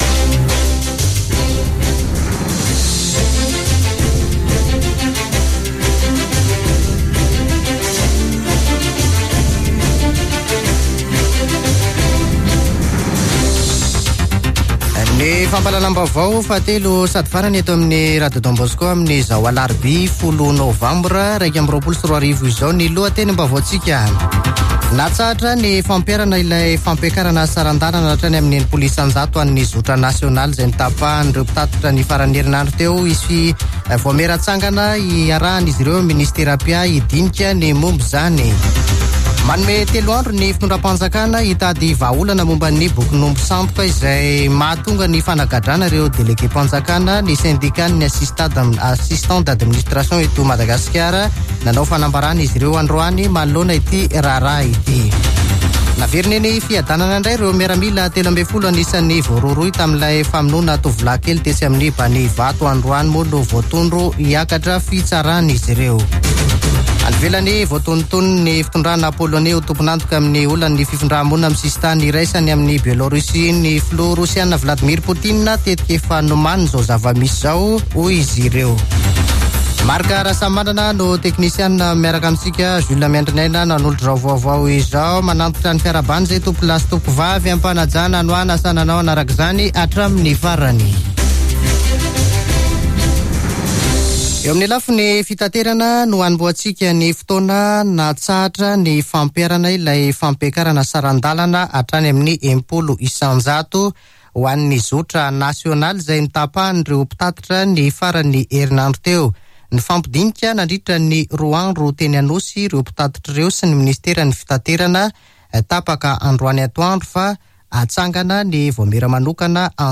[Vaovao hariva] Alarobia 10 novambra 2021